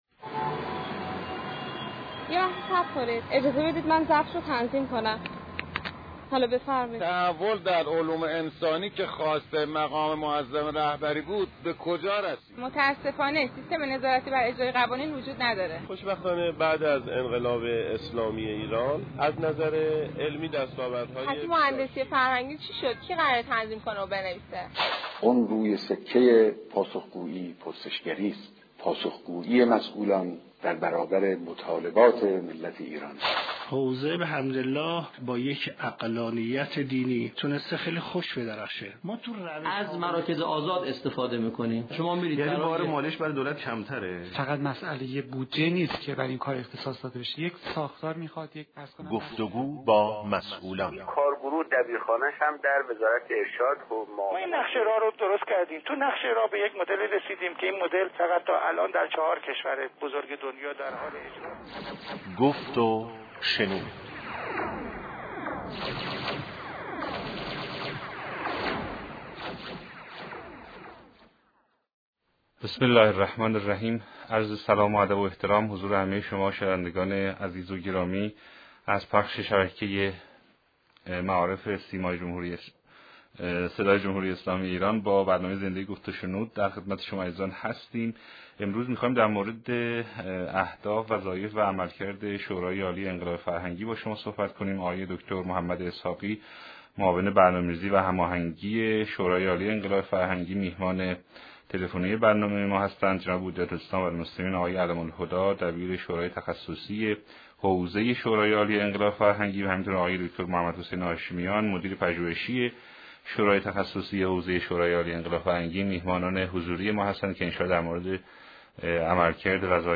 گفت و شنود شورای عالی انقلاب فرهنگی.mp3 متاسفانه مرورگر شما، قابیلت پخش فایل های صوتی تصویری را در قالب HTML5 دارا نمی باشد.